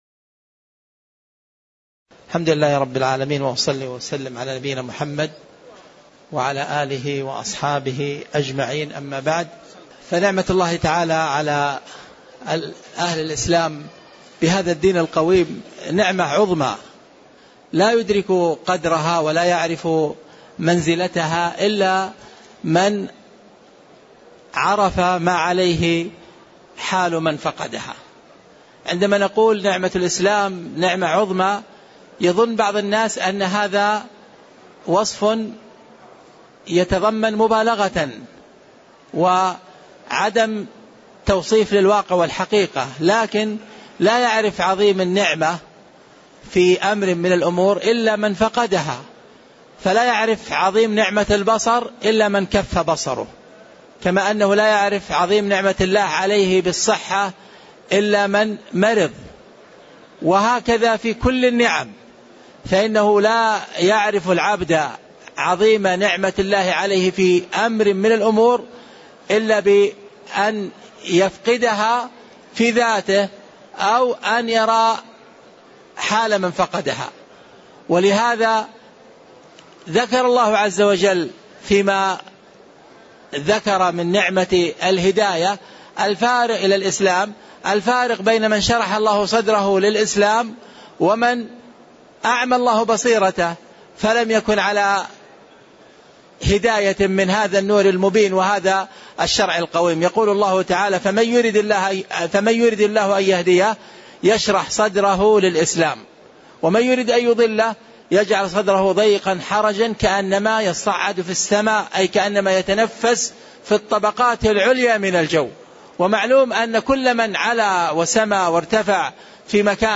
تاريخ النشر ١٩ شوال ١٤٣٧ هـ المكان: المسجد النبوي الشيخ